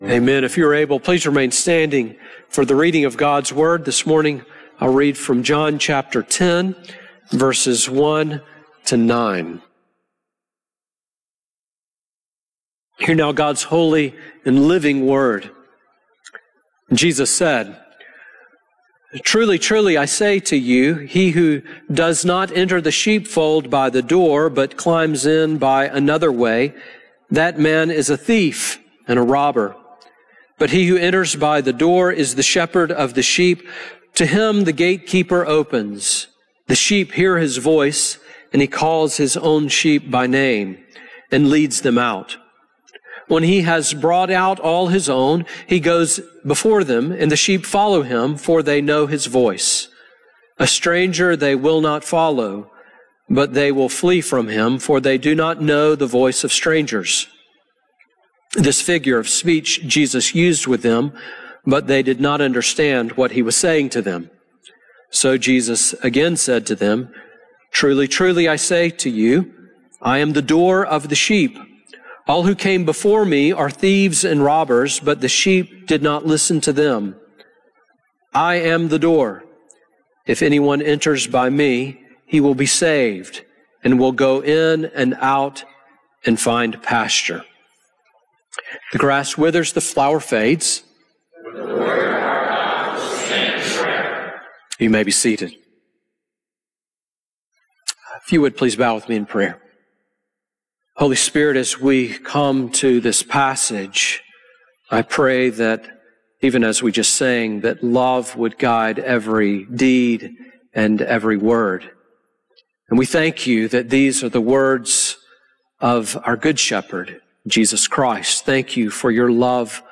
Sermon on John 10:1-9 from March 15